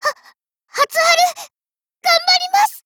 Cv-30121_warcry.mp3